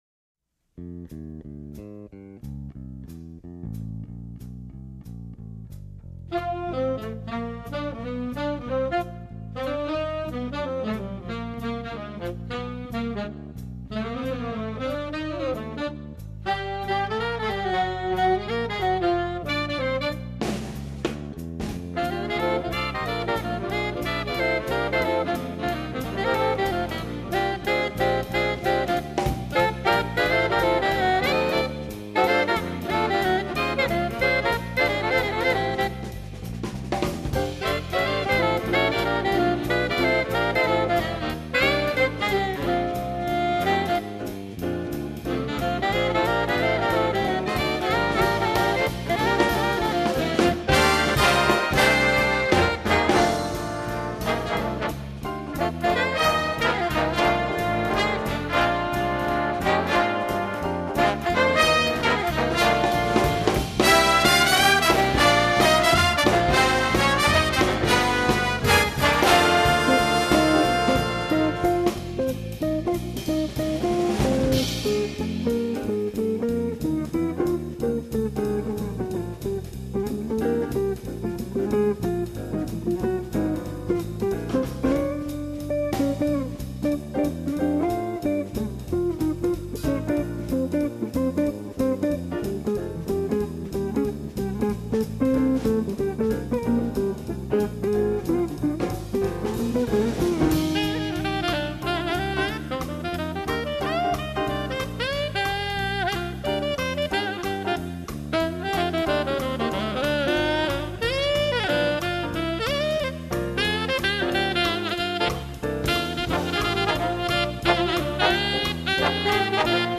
publisher's sample
features the saxophone section